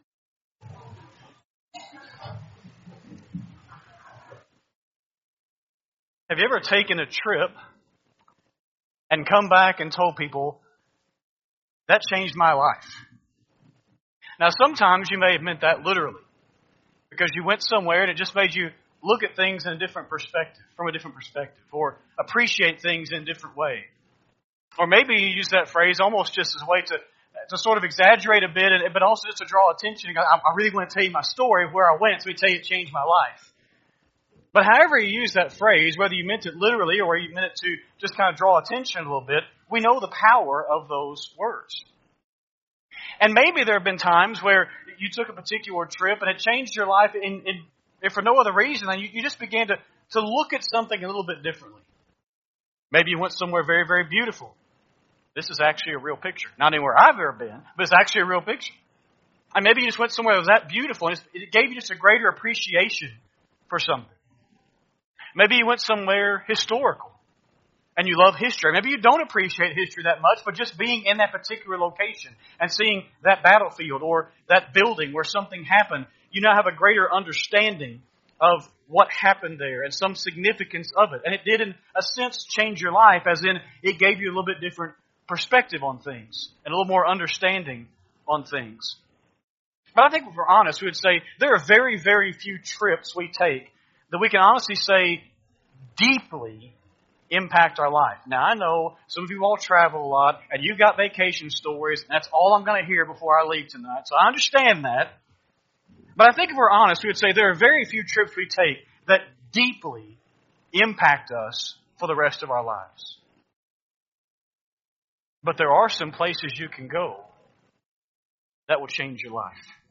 2-2-25-Sunday-PM-Sermon.mp3